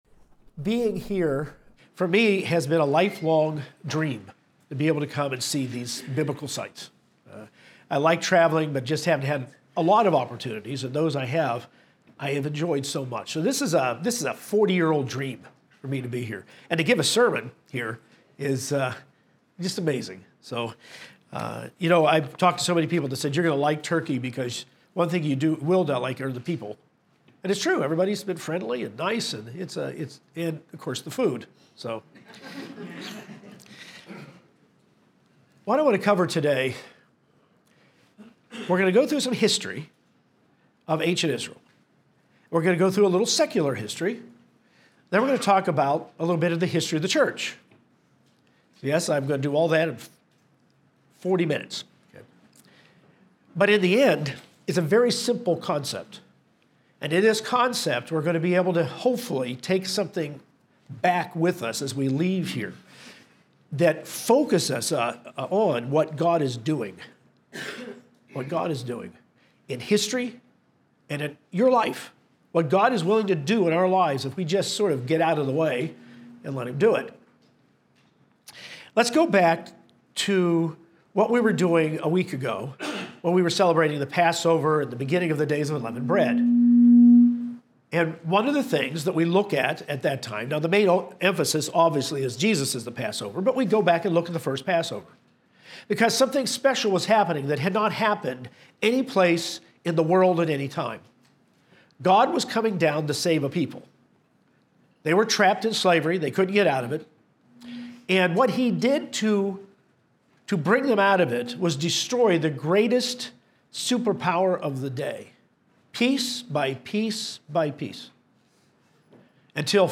This message was given in Turkey. The ancient Israelites left Egypt on the road to the Promised Land.